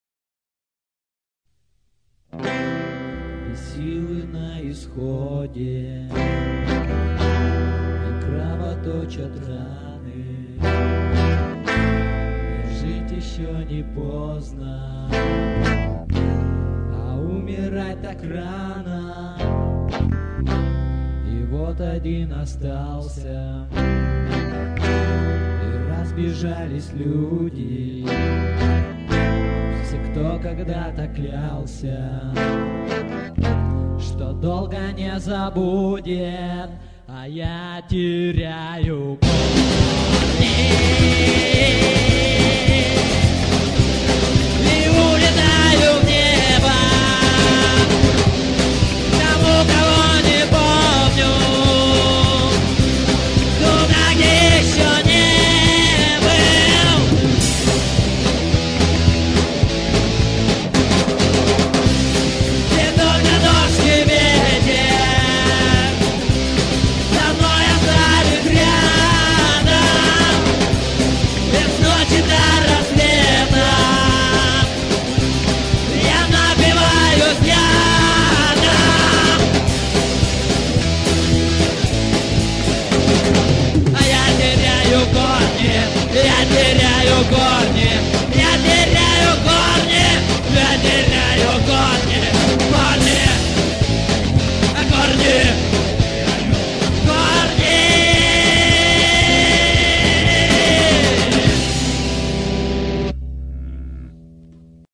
1-6: Записано на студии "Рай" весной 2004г.